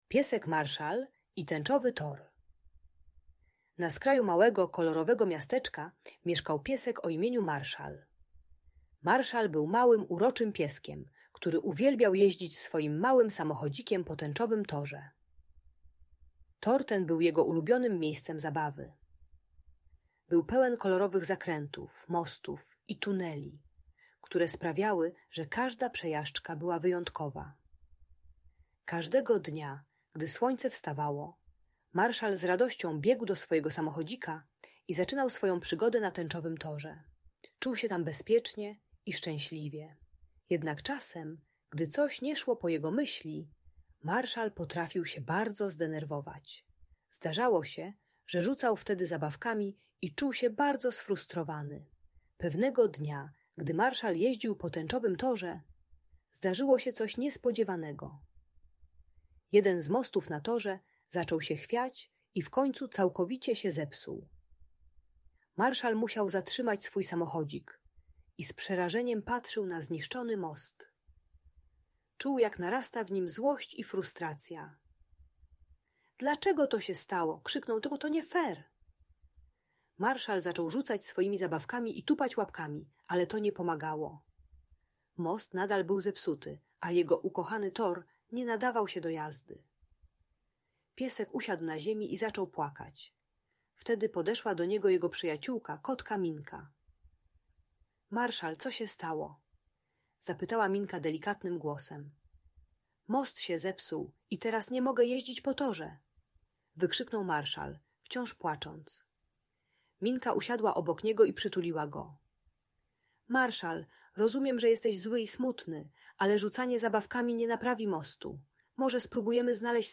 Story: Piesek Marshal i Tęczowy Tor - Bunt i wybuchy złości | Audiobajka